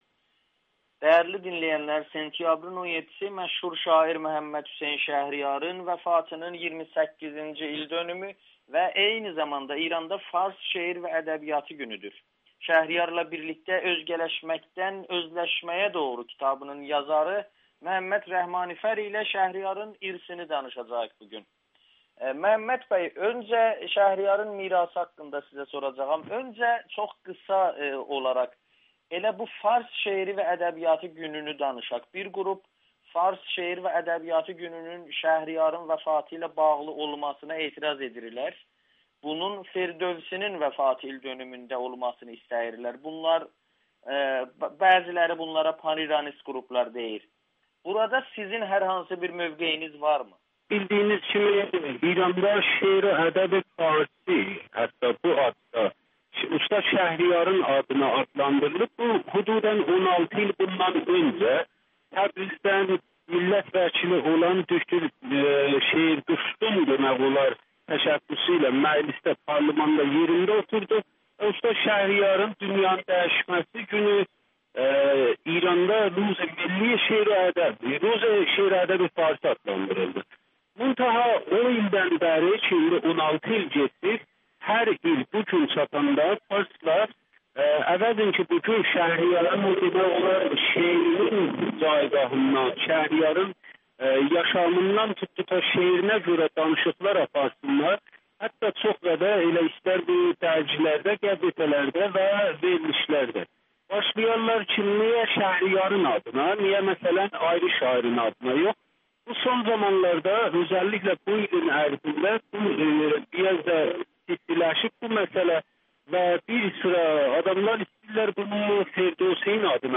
Şəhriyarın İran Azərbaycanına mirası türkcəni yaşatması idi [Audio-Müsahibə]